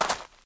sounds / step / grave3.ogg